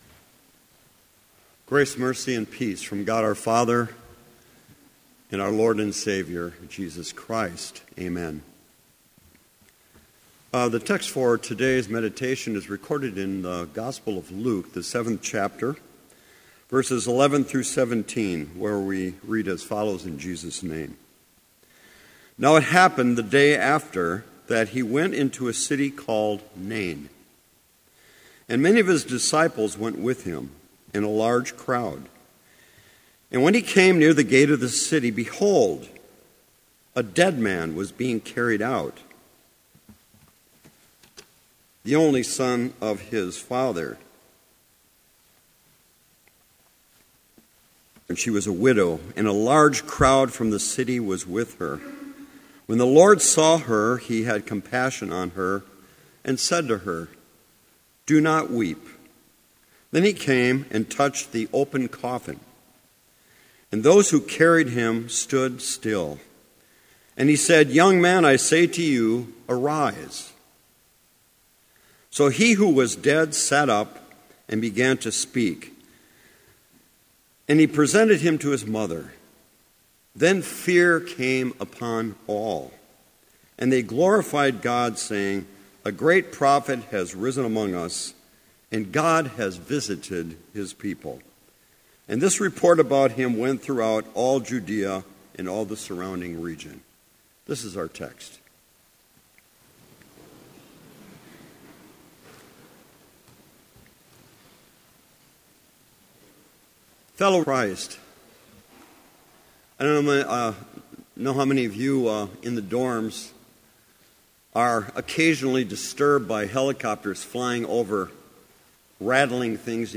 Complete service audio for Chapel - September 22, 2015
Order of Service Prelude Hymn 351, vv. 1-4, I Know that My Redeemer Lives Reading: Luke 7:11-17 Devotion Prayer # 113, p. 162 Hymn 351, vv. 7 & 8, He lives and grants me… Blessing Postlude